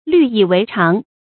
率以为常 lǜ yǐ wéi cháng 成语解释 成为经常的事。